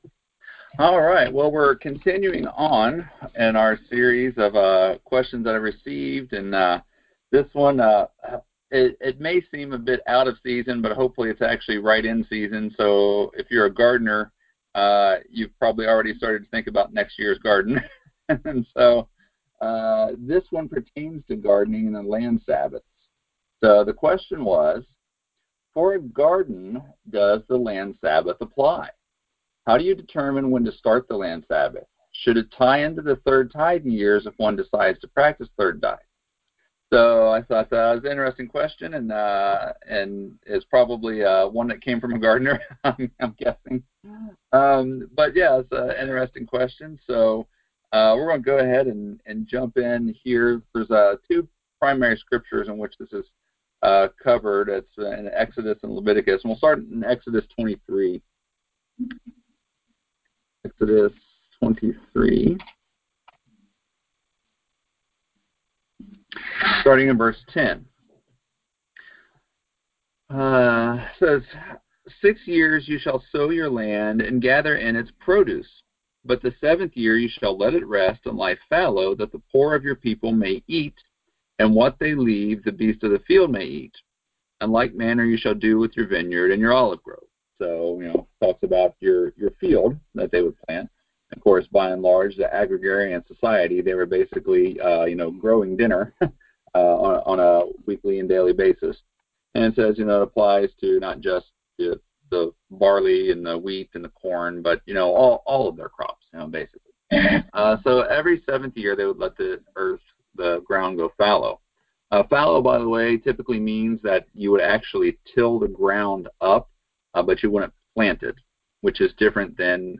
In this Bible Study we examine these question and see how God's plan provides not only spiritual but physical rest. NOTE: This study contains an interactive portion toward the ends. Some of the questions asked cannot be heard on the recording.